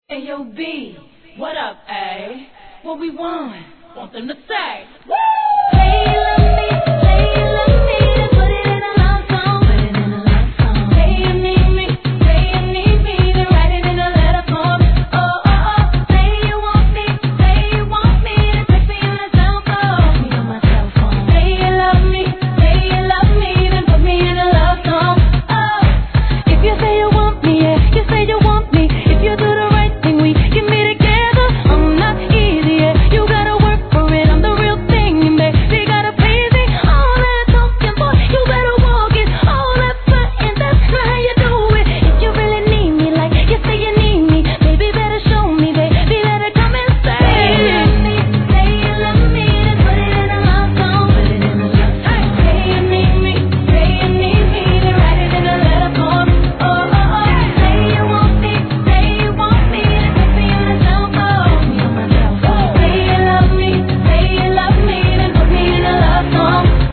HIP HOP/R&B
ピアノの美しいイントロから透き通る素晴しいヴォーカルを披露する傑作!!